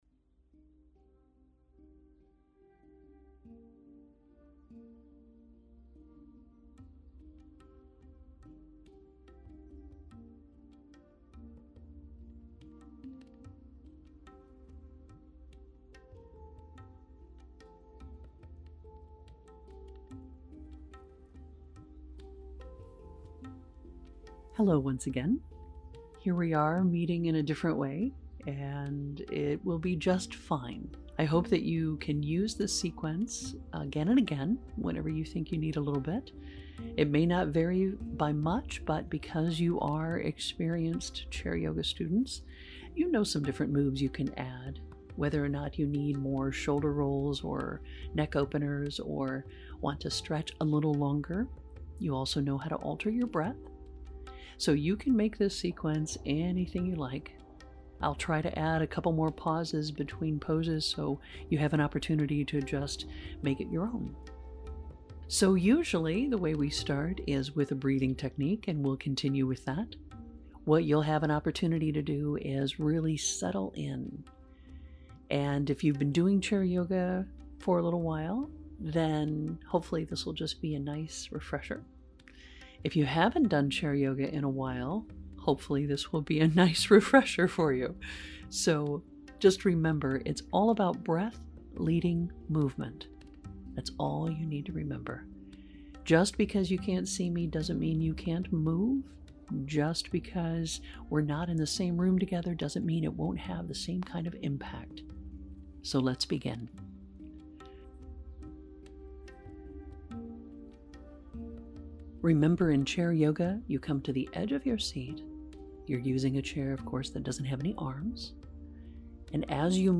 There are two auto-play sessions below (meaning nothing to download): * A 35-minute Chair Yoga session. The main prop you need is a stable chair without arms.